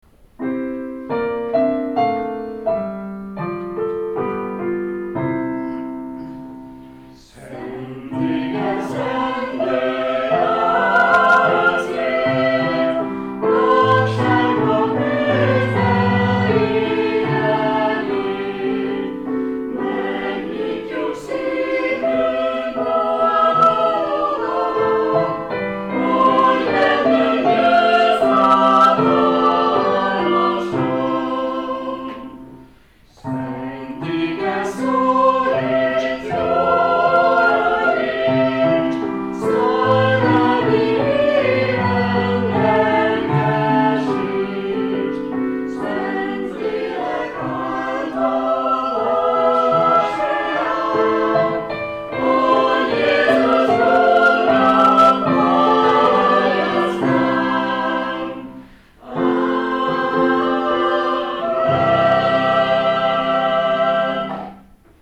Az igehirdetés előtt ezt énekelte a baptista kórus.